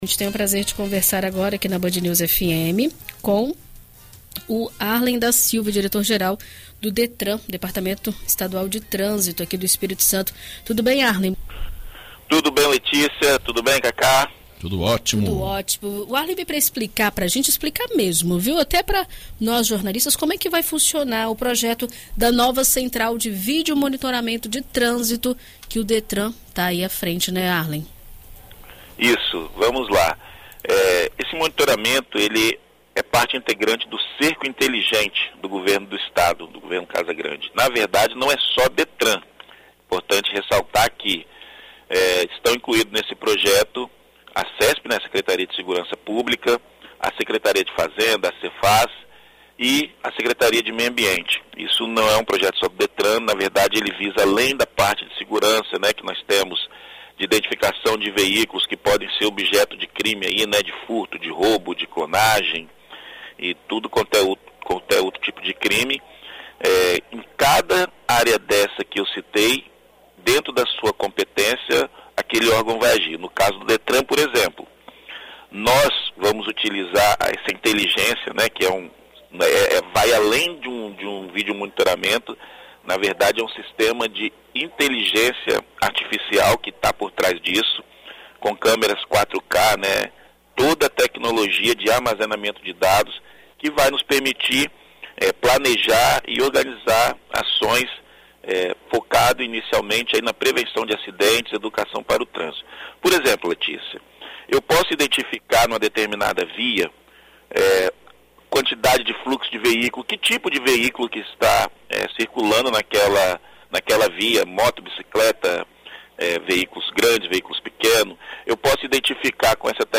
Em entrevista à BandNews FM Espírito Santo nesta quarta-feira (24), o diretor geral do Detran/ES, Harlen da Silva, explica como irá funcionar a central e explica a função dela, esclarecendo detalhes a respeito das autuações e infrações.